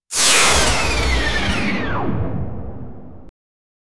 Blaster Sound Effects - Free AI Generator & Downloads
The Jedi use their lightsabers to deflect on coming blaster fire from the battle droids. 0:19 sci-fi, star wars, blaster sound, energy 0:03 futurisctic shift shooting sound of a blaster, with slight spark beams sounding intercalated 0:04
futurisctic-shift-shootin-7g7tkje5.wav